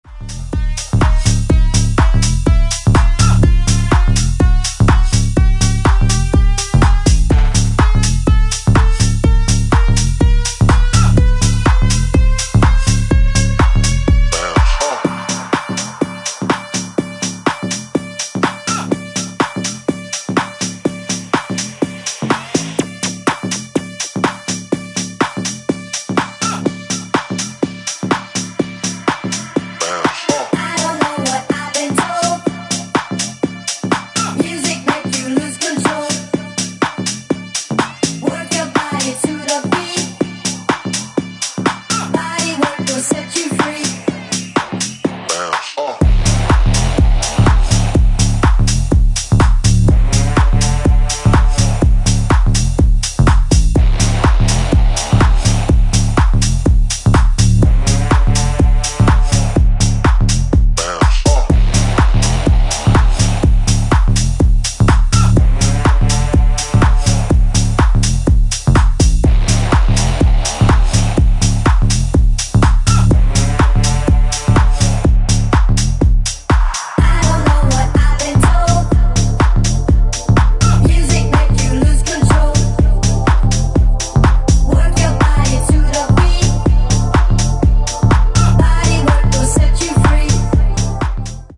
アップ・ハウスでフロア重視の全4バージョン
ジャンル(スタイル) HOUSE / DISCO HOUSE